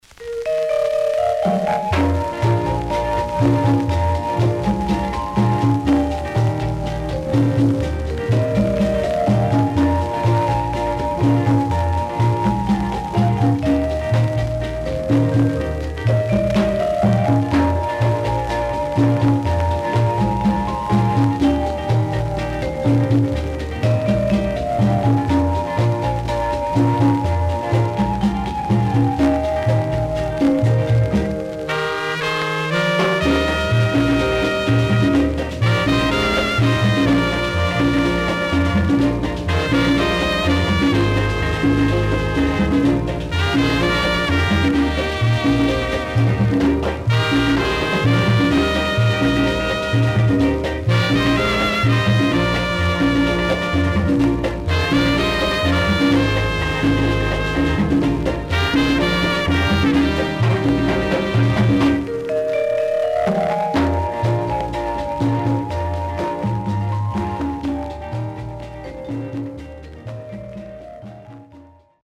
Rare.Twist Number & Good Inst
SIDE A:全体的にチリノイズがあり、所々プチパチノイズ入ります。